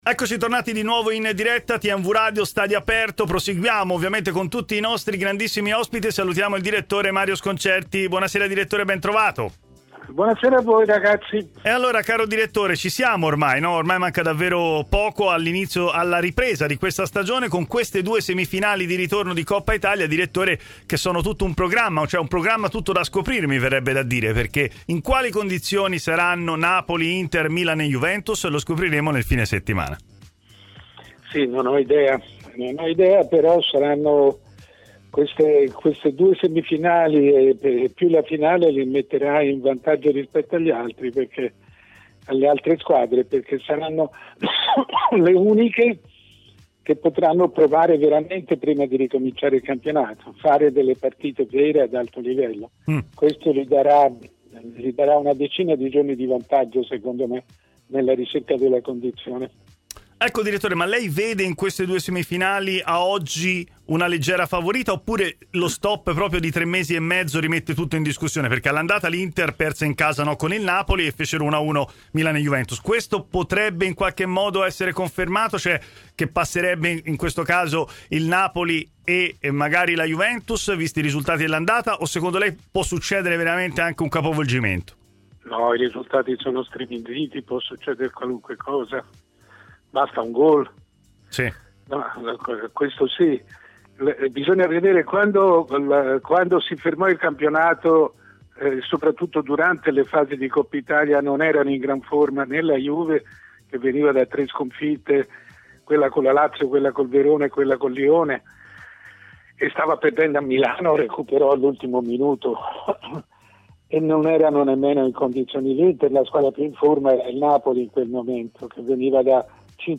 Il direttore Mario Sconcerti è intervenuto ai microfoni di TMW Radio, nel corso della trasmissione Stadio Aperto